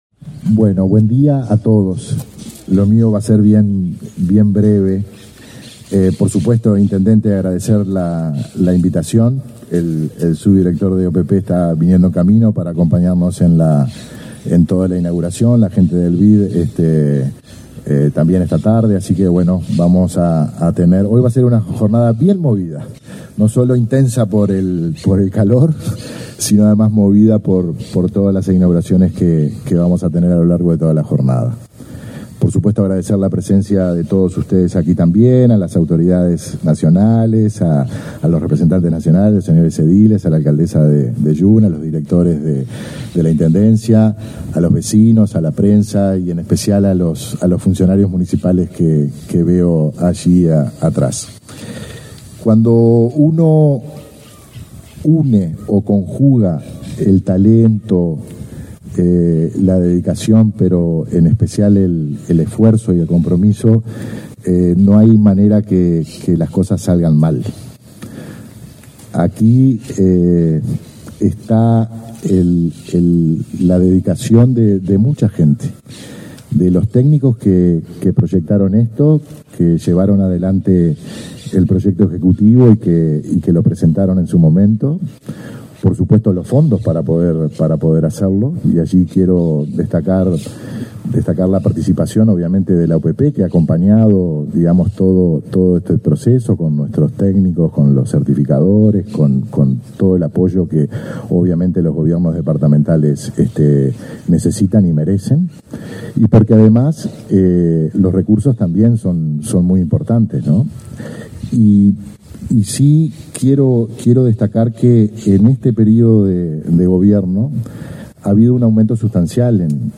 Acto de inauguración de obras de la OPP en Fray Bentos
Participaron en la actividad el director de Infraestructura de la OPP, Guillermo Bordoli; el subsecretario nacional de Deporte, Enrique Belo, y el subdirector de la OPP, Benjamín Irazabal.